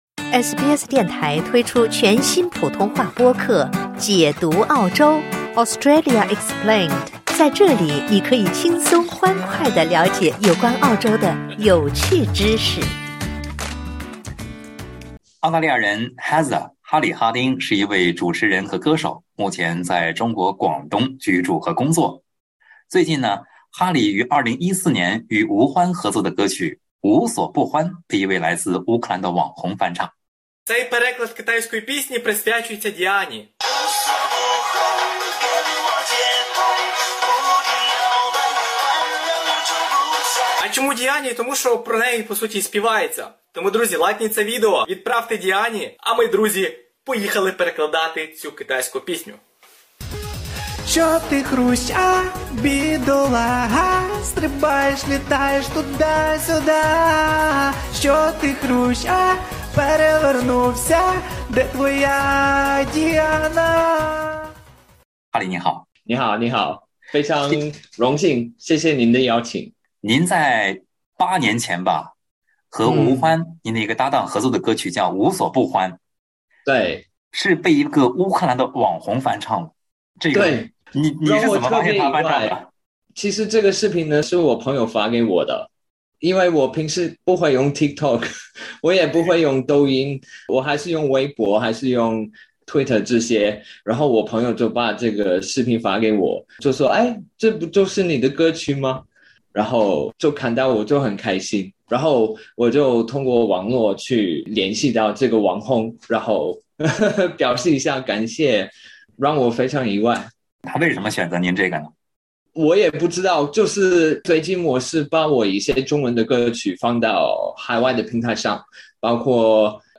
在采访中